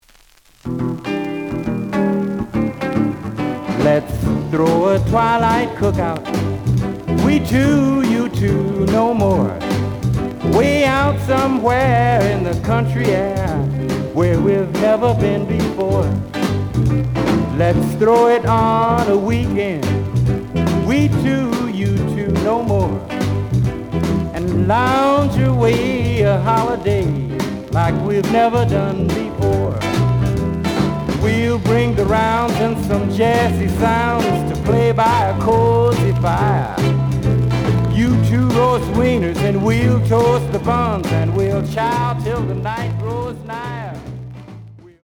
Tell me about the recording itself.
The audio sample is recorded from the actual item. Looks good, but slight noise on B side.)